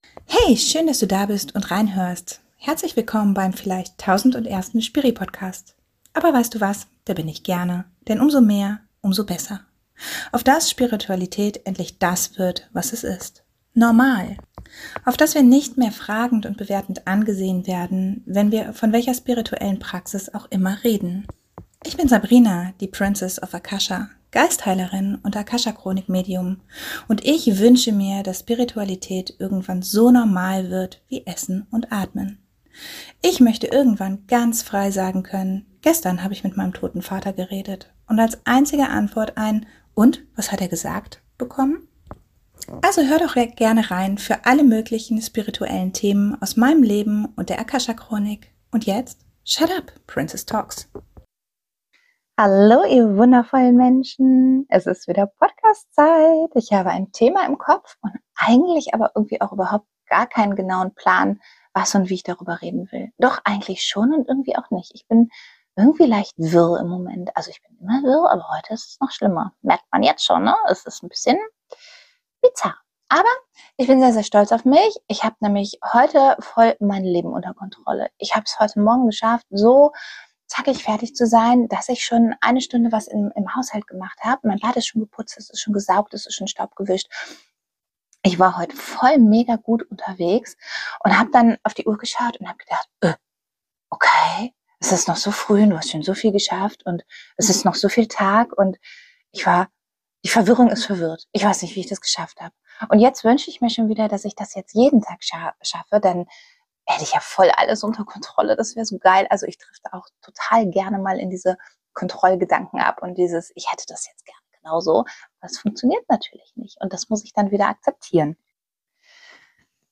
Wo kommen sie her, was tun sie und vor allem wie wirst du sie wieder los? All das erklär ich dir in meiner heutigen Podcastfolge über Fremdenergien - auch wenn ich hörbar leicht verwirrt unterwegs bin, aber was soll ich tun?